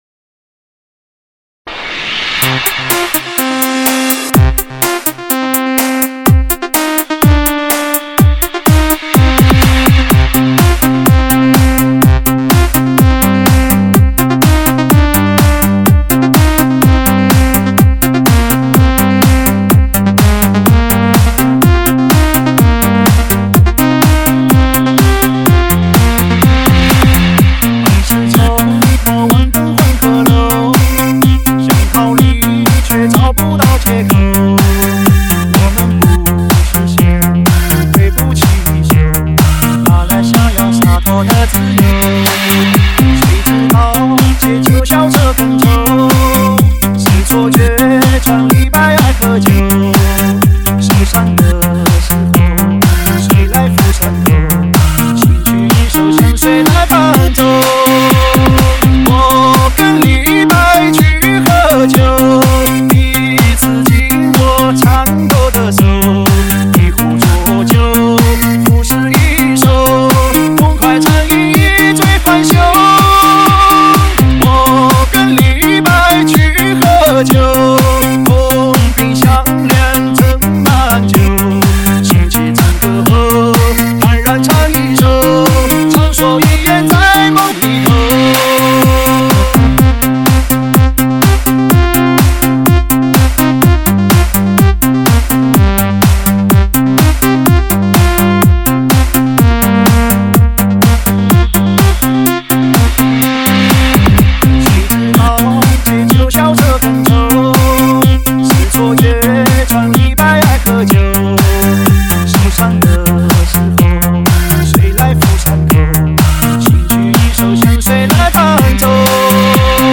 前卫节奏最嗨的享受